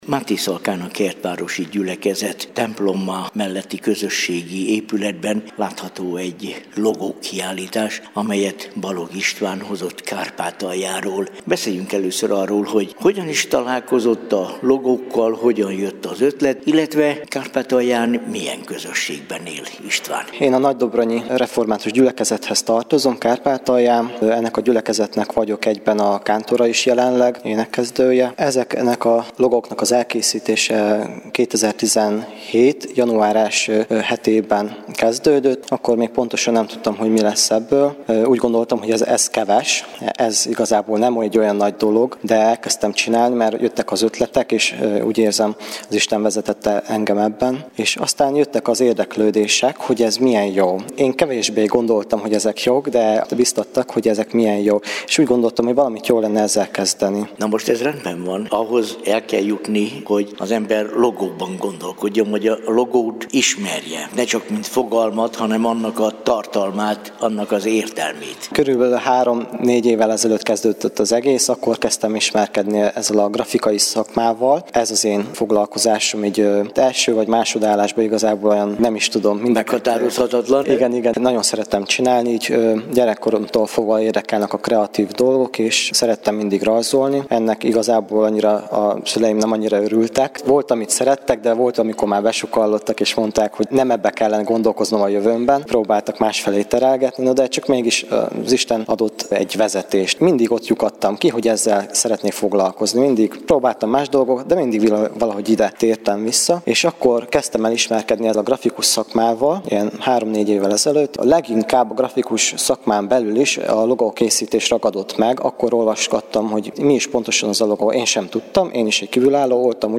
interjút